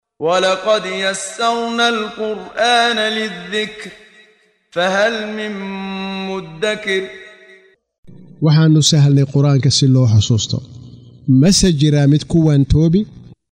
Waa Akhrin Codeed Af Soomaali ah ee Macaanida Suuradda Al-Qamar ( Dayaxa ) oo u kala Qaybsan Aayado ahaan ayna la Socoto Akhrinta Qaariga Sheekh Muxammad Siddiiq Al-Manshaawi.